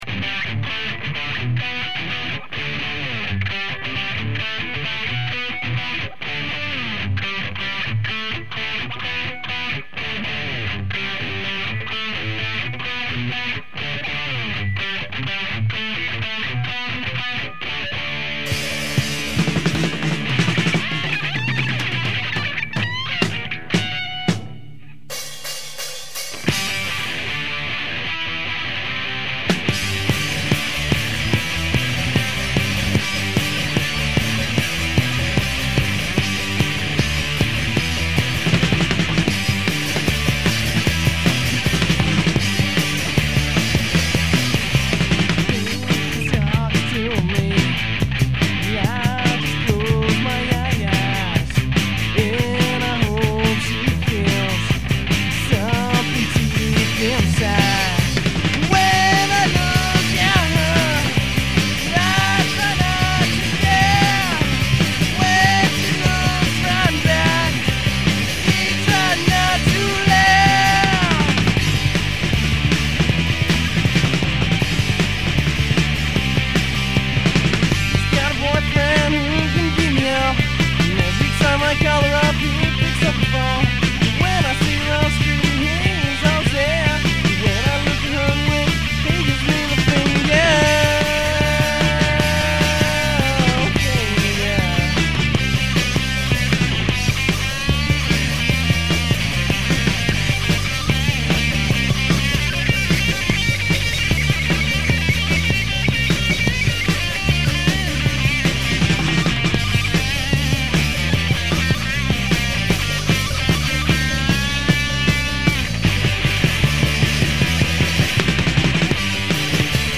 has a slight punk feel